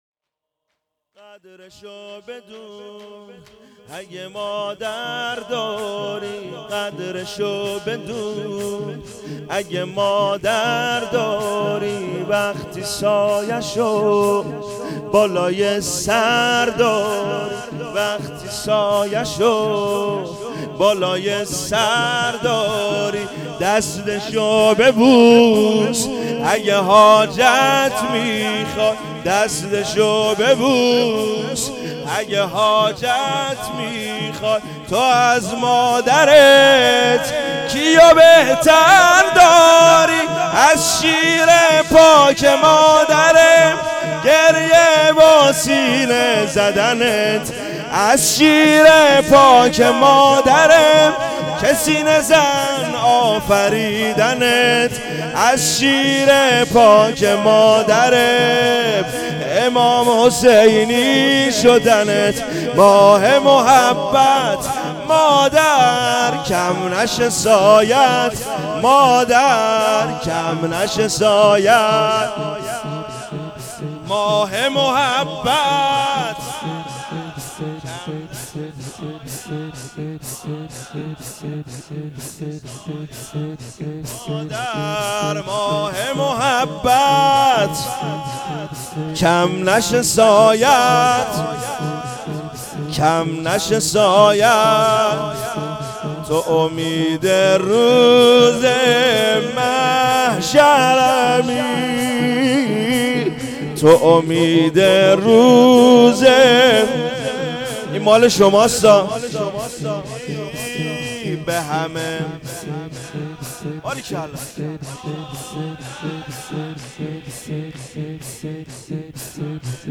شور
شب چهارم مراسم دهه اول فاطمیه ۹۹